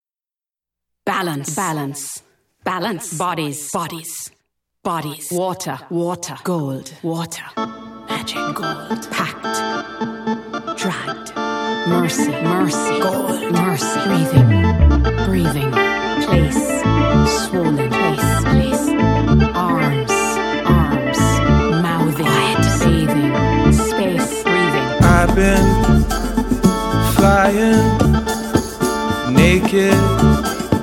Basse (instrument)